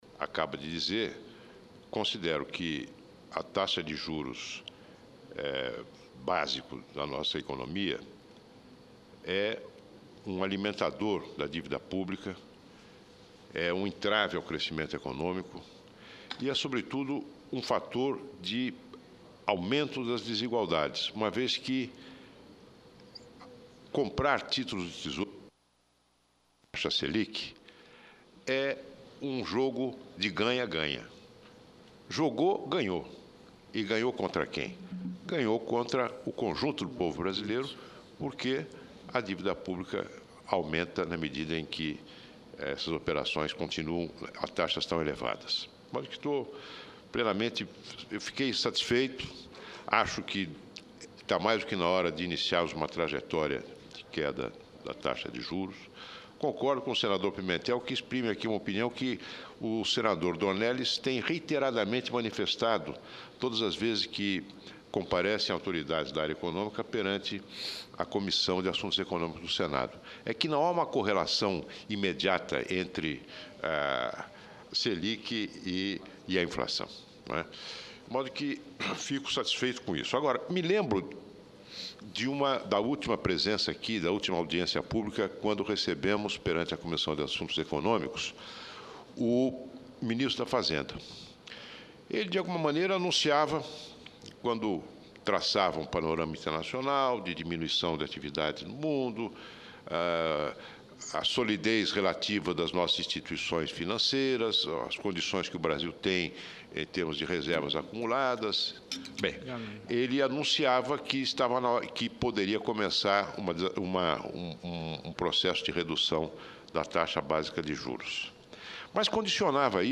Senador Walter Pinheiro (PT-BA) chamou atenção, em Plenário, nesta sexta-feira (02/09), sobre a decisão de quarta-feira do Copom de reduzir a Taxa Selic. Comentou também a entrega do Projeto de Lei Orçamentária feita esta semana pela ministra do Planejamento, Miriam Belchior.